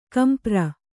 ♪ kampra